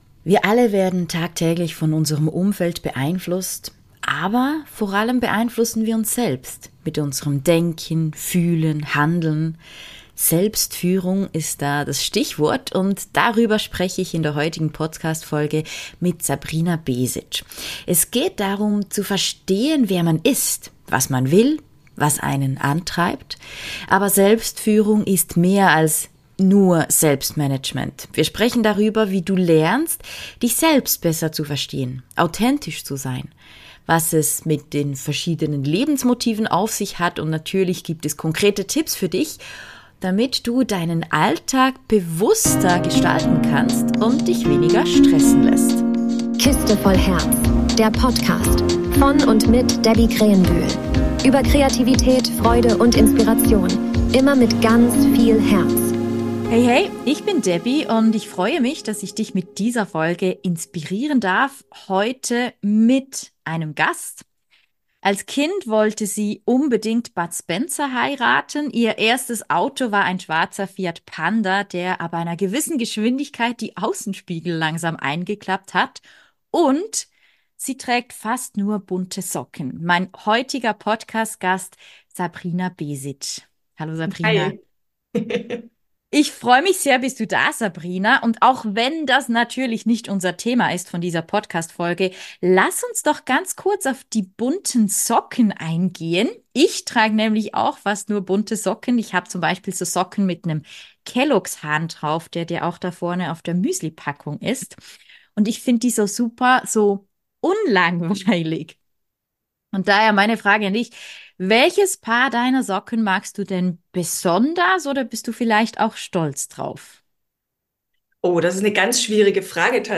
ein Gespräch über Selbstführung